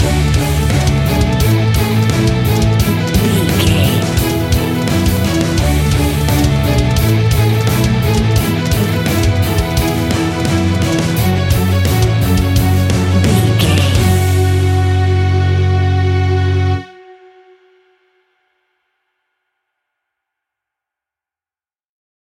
Epic / Action
Fast paced
Aeolian/Minor
F#
hard rock
instrumentals
Heavy Metal Guitars
Metal Drums
Heavy Bass Guitars